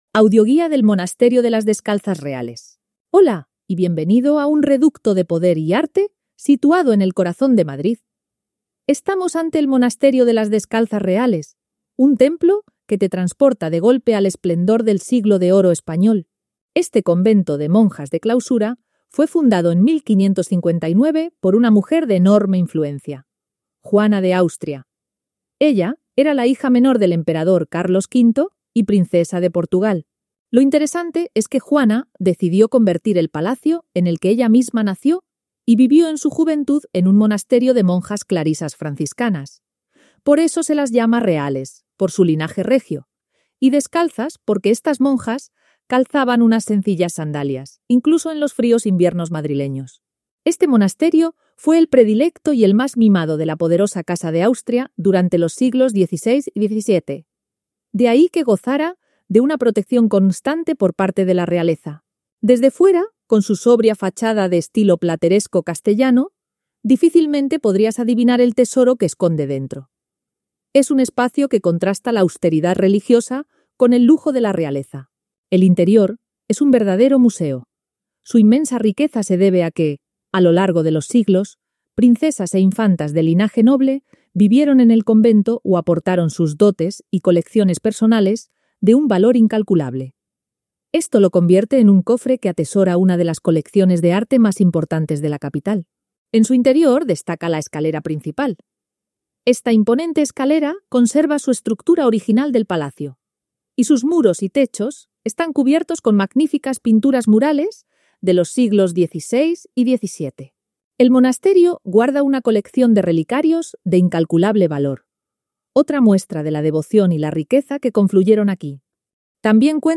Audioguía: Monasterio de la Descalzas Reales
AUDIOGUIA-MONASTERIO-DESCALZAS-REALES.mp3